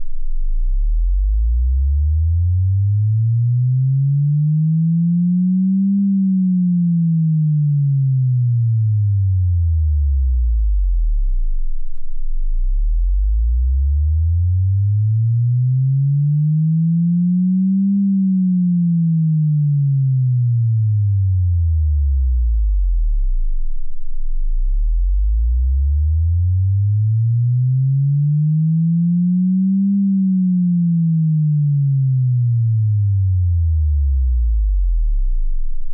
ljudfil (6,3Mb .wav fil) med linjärt ton svep 10-200Hz Genererad med 'NCH Tone Generator') spelar i loop med FooBar2000 programvara på PC.